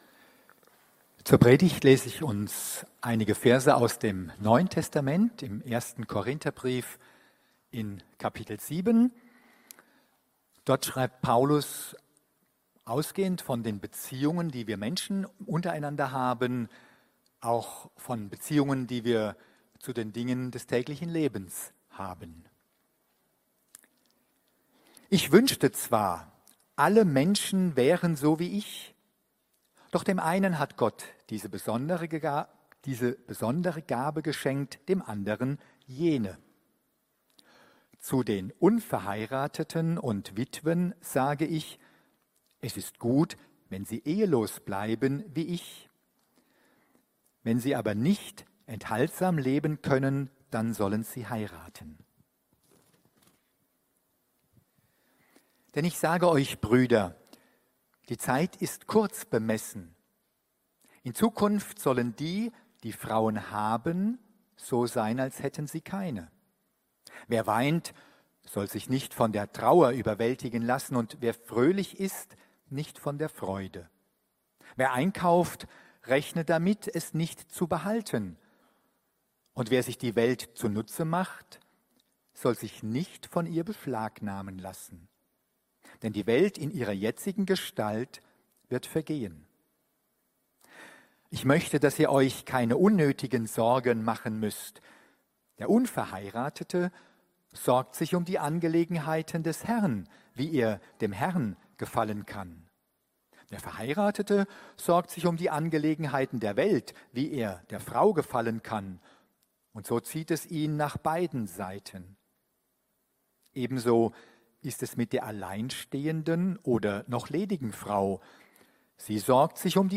Predigten – Er-lebt. Eine Kirche für Landau